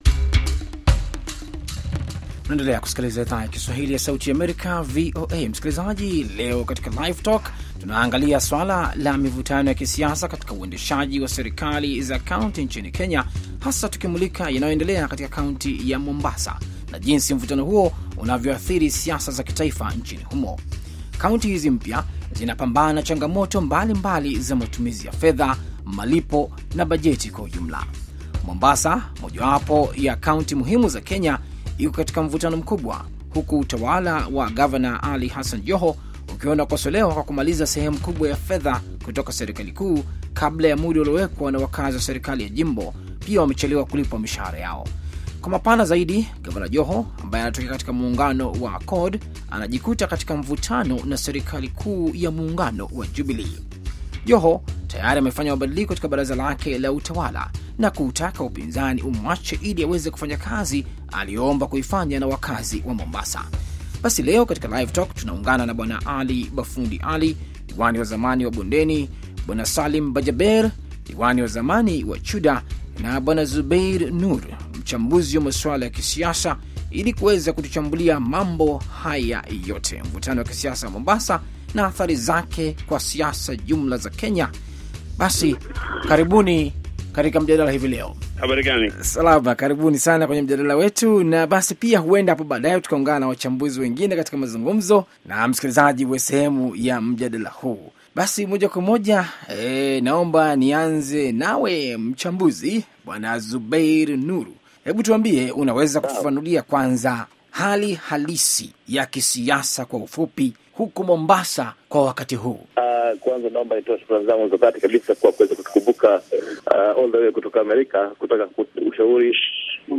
"Live Talk" Siasa za Mombasa na mustakbal wake
Mjadala juu ya Siasa za Mombasa